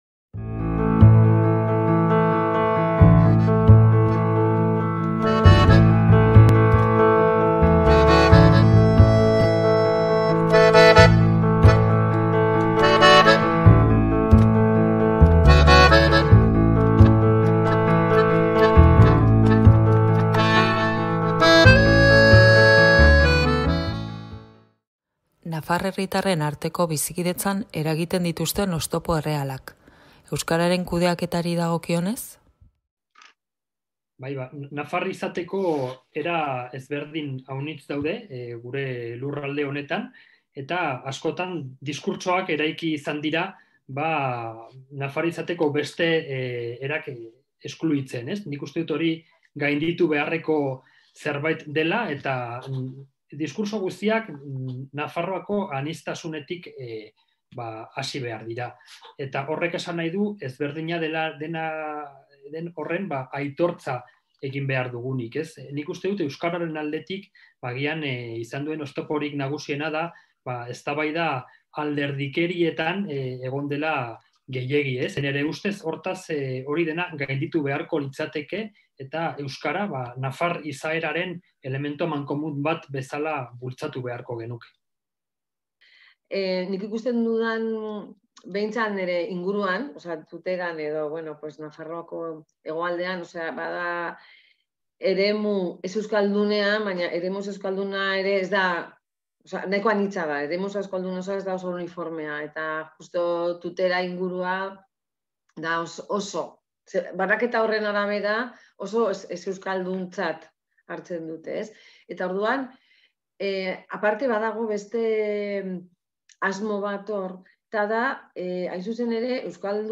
Bi irakaslek hitz egin dute Nafarroako bizikidetzari buruz.